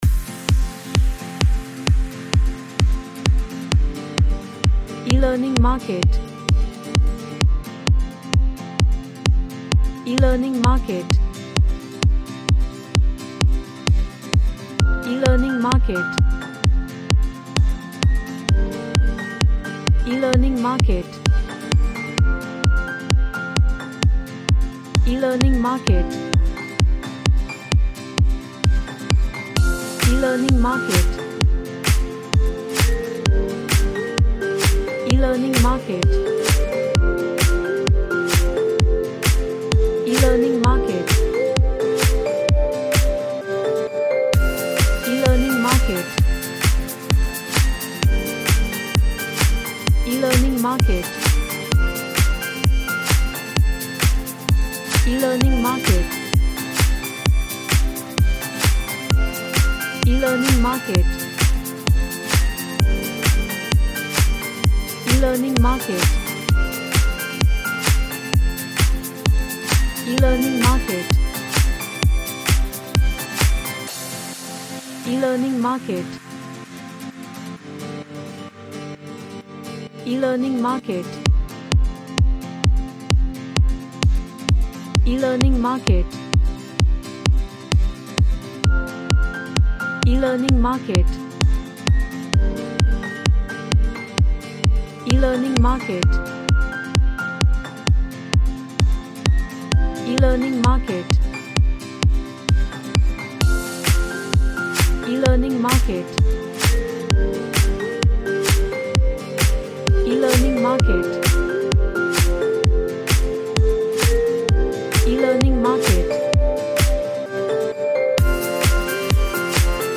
An upbeat track with bagpipe essence
UpbeatUplifting